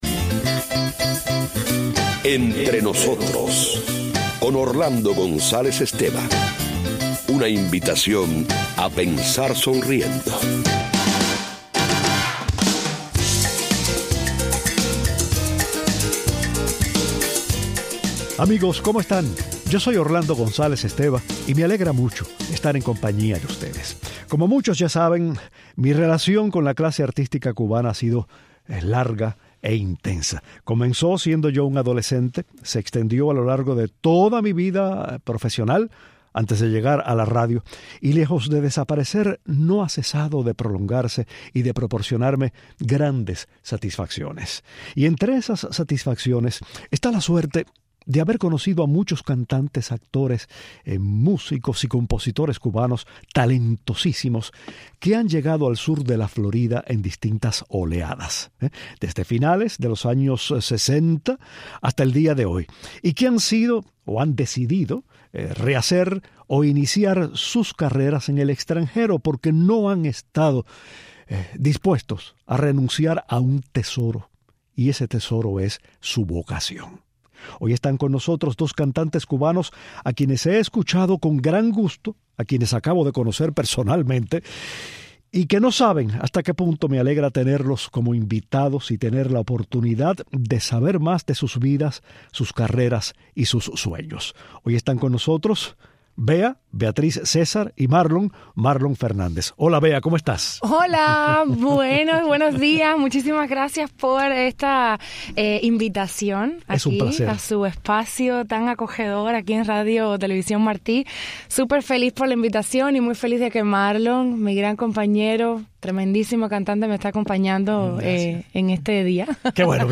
Los excelentes cantantes cubanos llenan de alegría el programa hablando de sus vidas, sus carreras, sus planes y entonando espontánemente, sin acompañamiento, un fragmento de la canción que acaban de grabar a dos voces.